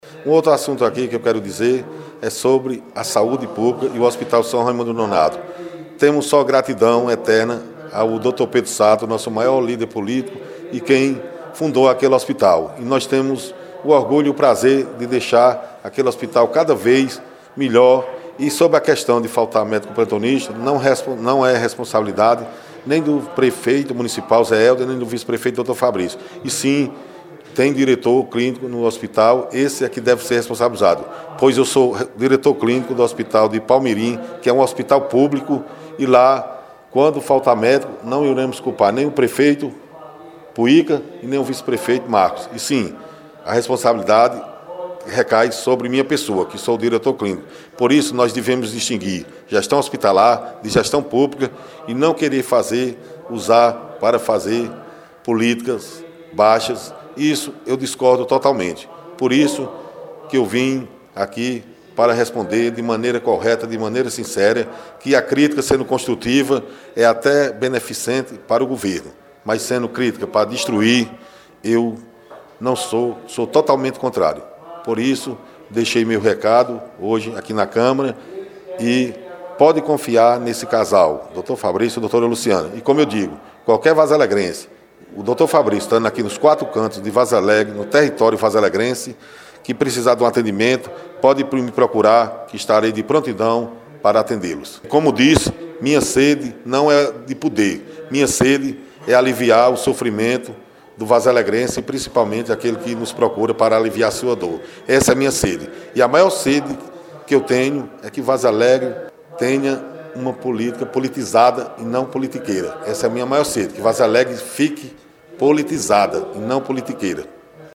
Acompanhe um áudio em que o médico fala de sua participação: